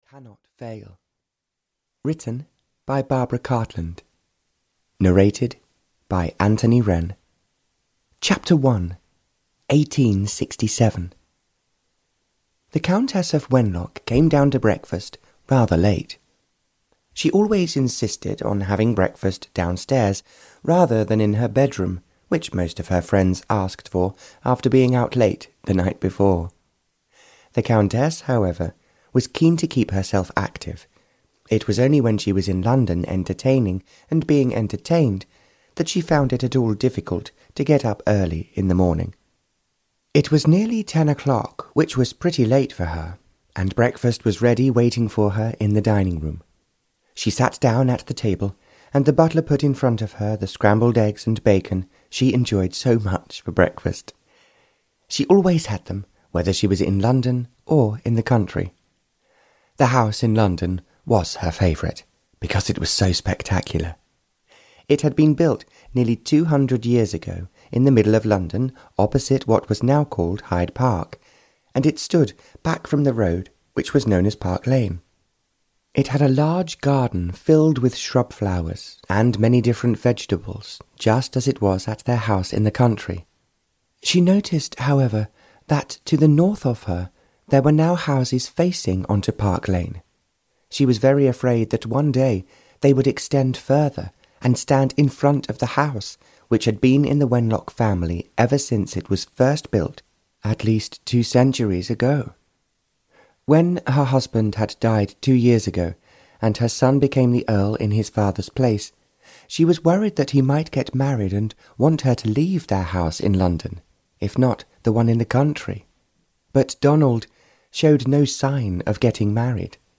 Audio knihaLove Cannot Fail (Barbara Cartland's Pink Collection 155) (EN)
Ukázka z knihy